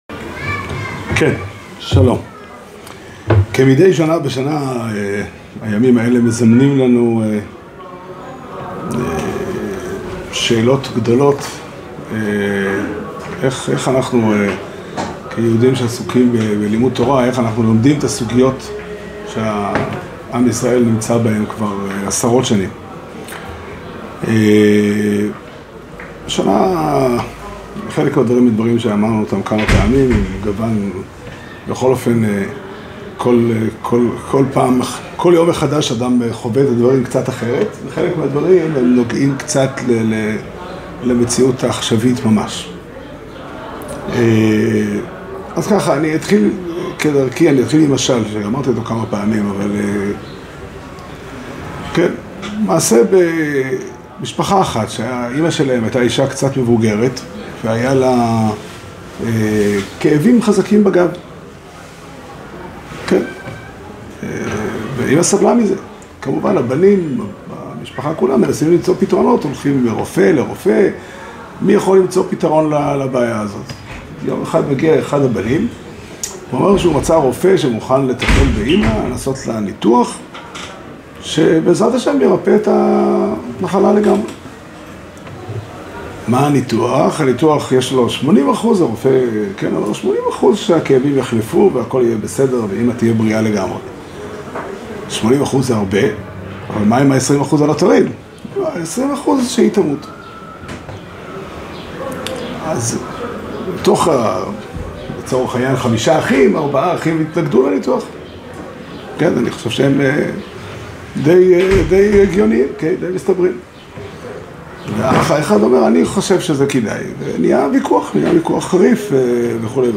שיעור שנמסר בבית המדרש פתחי עולם בתאריך ה' באייר תשפ"ג